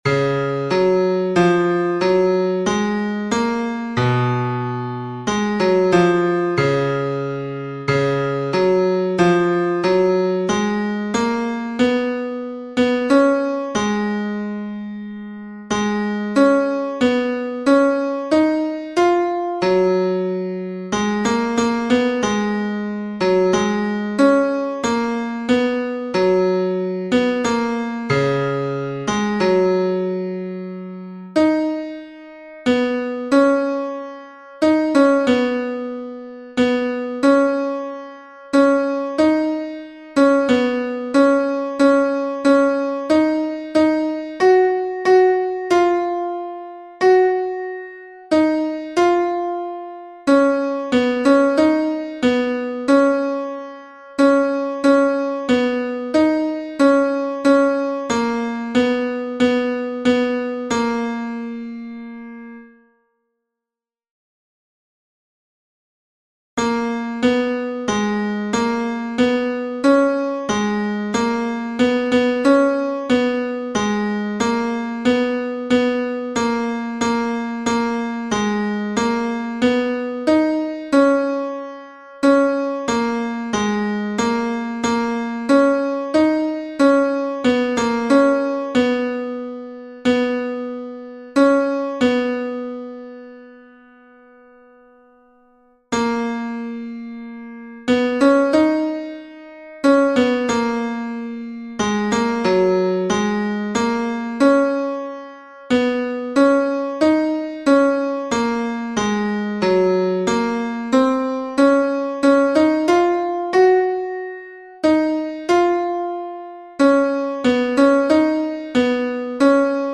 Les pistes par pupitre contiennent un couplet, un refrain (sans reprise) et la suite à partir de la page 5 (mesure 73).
tenors-mp3 29 octobre 2023